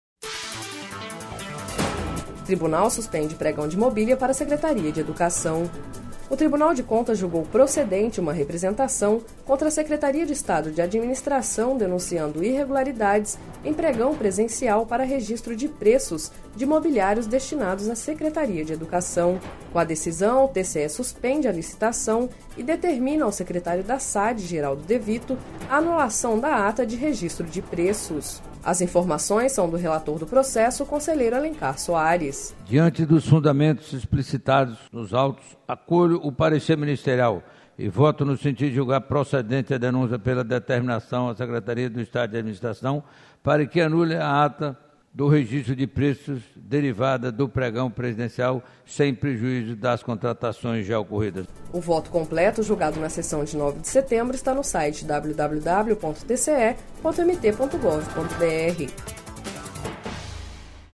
Sonora: Alencar Soares– conselheiro do TCE-MT